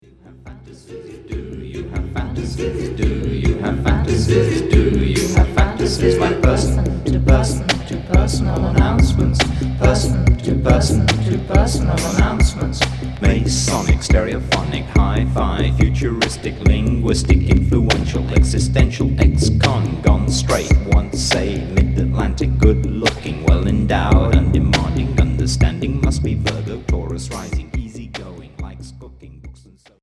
Cold minimal expérimental Troisième 45t retour à l'accueil